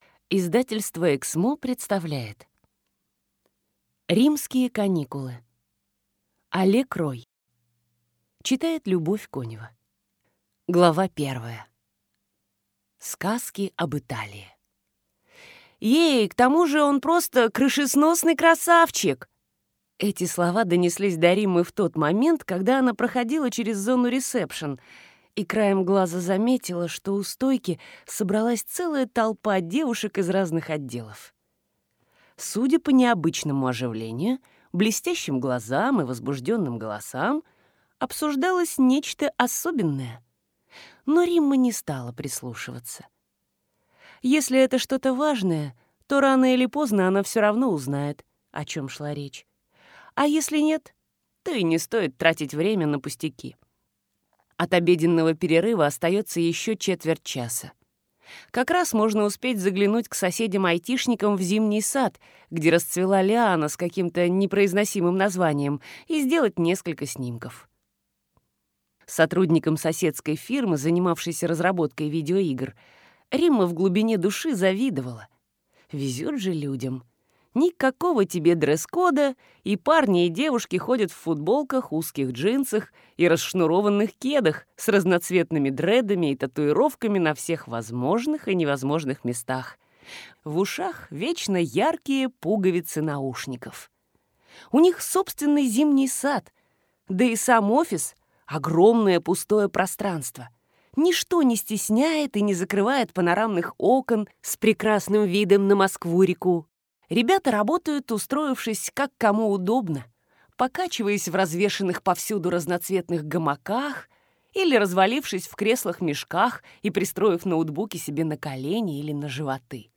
Аудиокнига Римские каникулы | Библиотека аудиокниг